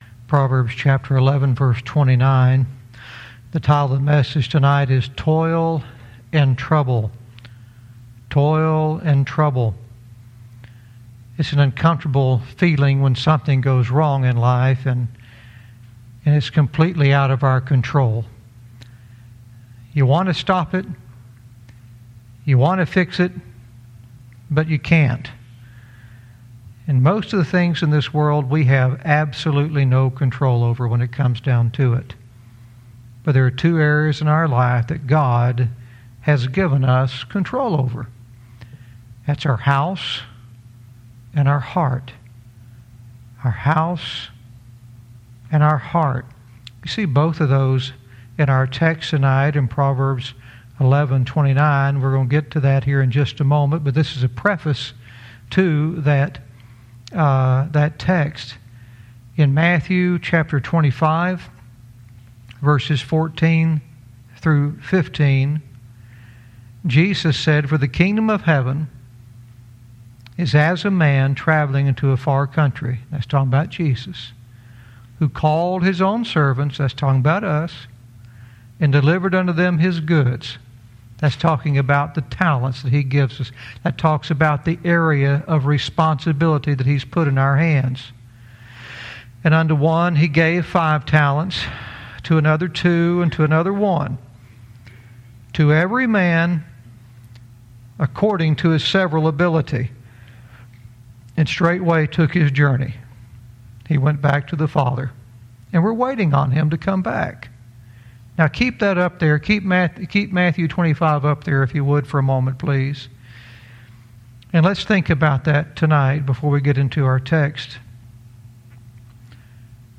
Verse by verse teaching - Proverbs 11:29 "Toil & Trouble"